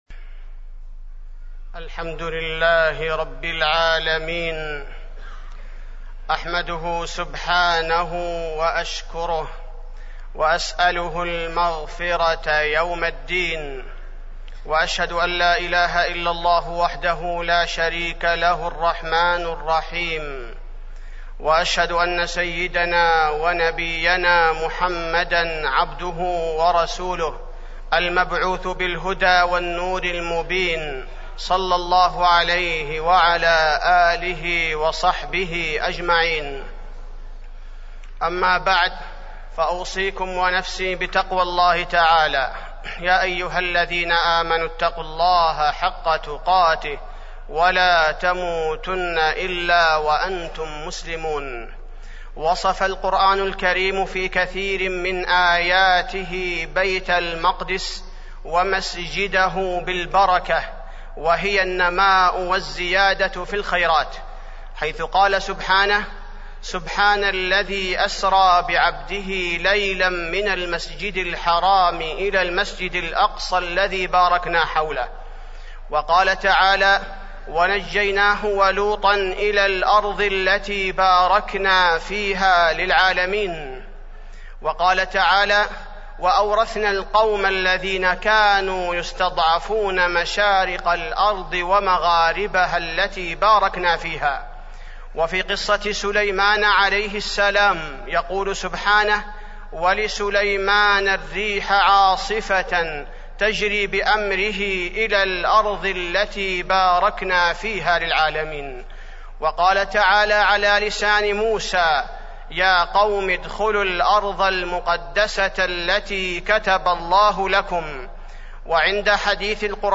تاريخ النشر ٦ ربيع الأول ١٤٢٦ هـ المكان: المسجد النبوي الشيخ: فضيلة الشيخ عبدالباري الثبيتي فضيلة الشيخ عبدالباري الثبيتي القدس